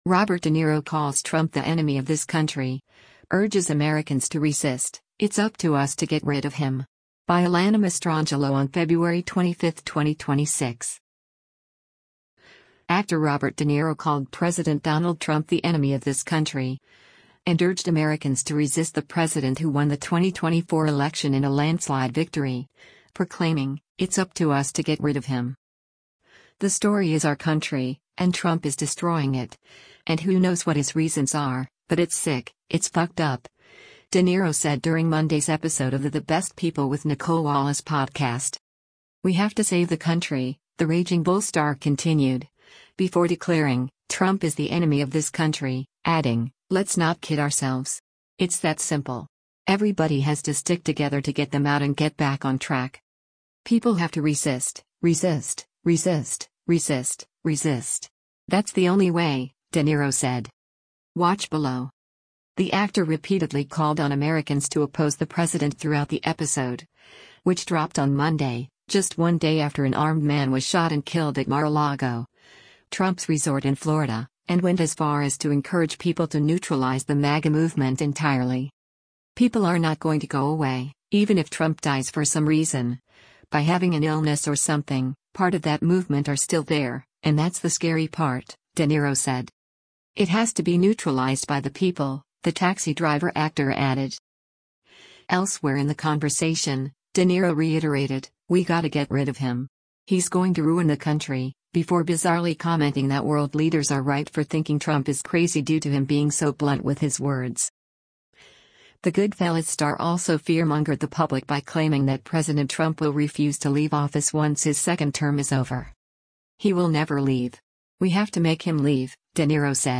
“The story is our country, and Trump is destroying it, and who knows what his reasons are, but it’s sick, it’s fucked up,” De Niro said during Monday’s episode of the The Best People With Nicolle Wallace podcast.
Toward the end of the interview — after calling on Americans to neutralize more than 77 million of their fellow citizens — De Niro ironically asserted, “You have to lift people up, you have to bring them together, period. You can’t divide people,” while choking up in tears.